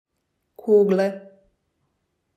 4. Kugle (click to hear the pronunciation)